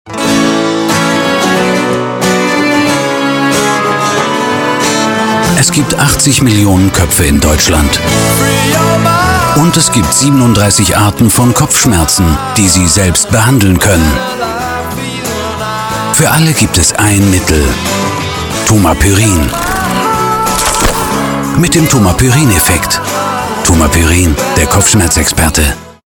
werbesprecher, off-sprecher, hörspiele, hörbücher, station-voice, schauspieler, einige dialekte, tiefe stimme
norddeutsch
Sprechprobe: Werbung (Muttersprache):